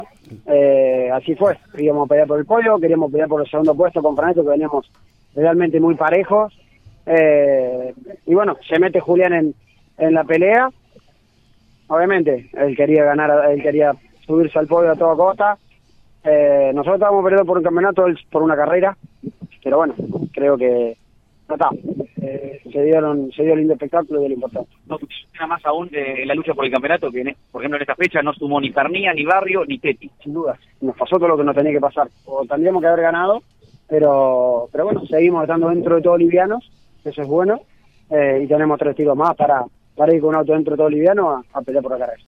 Estas son las respectivas entrevistas: